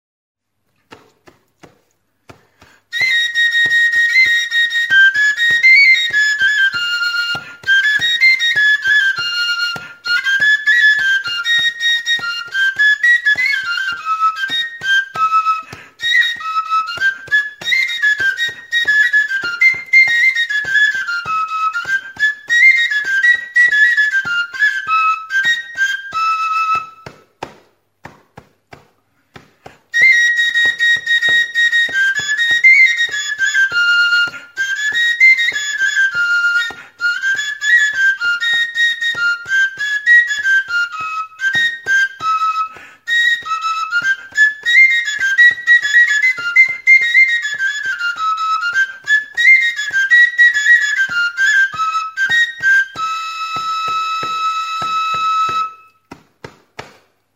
Aerófonos -> Flautas -> Recta (de una mano) + flautillas
Grabado con este instrumento.
FLAUTA ROCIERA
Hiru zuloko flauta zuzena da. Zurezko tutua eta adarrezko ahokoa du. Lau tokitan eraztun modukoak tailaturik ditu.